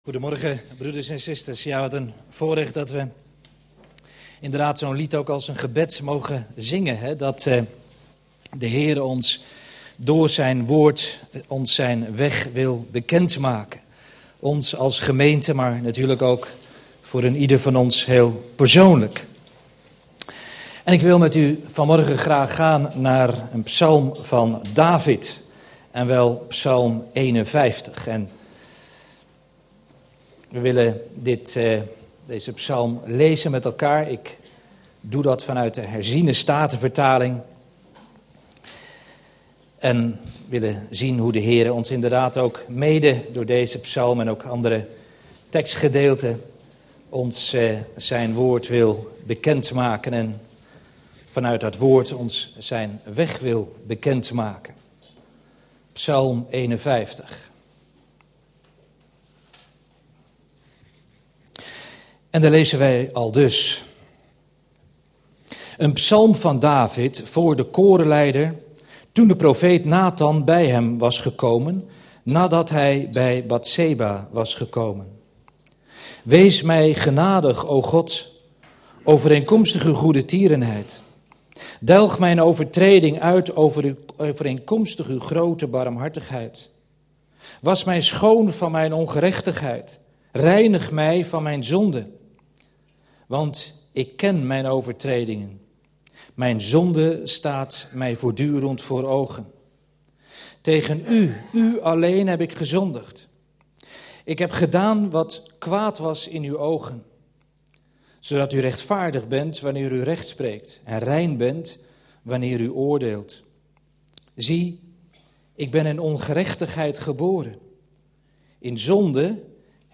In de preek aangehaalde bijbelteksten (Statenvertaling)Psalmen 511 Een psalm van David, voor den opperzangmeester.